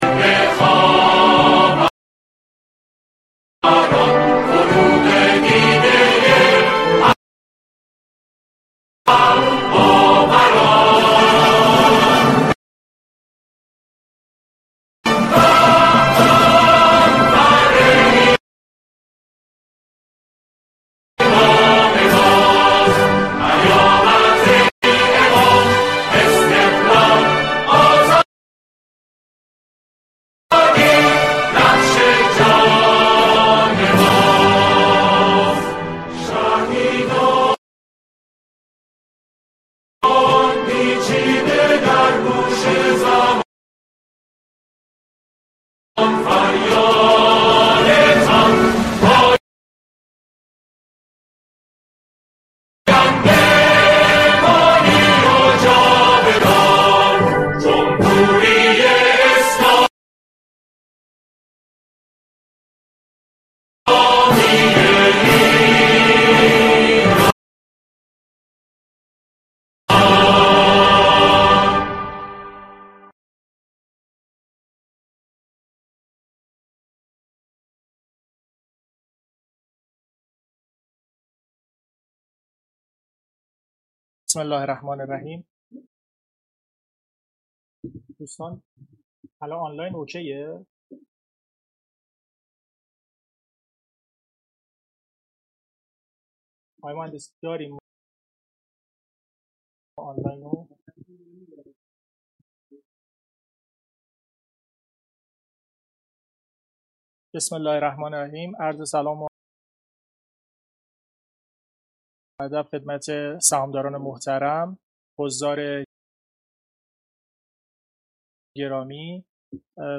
کنفرانس آنلاین پرسش و پاسخ سهامداران و مدیران شرکت مهرکام پارس- نماد:خمهر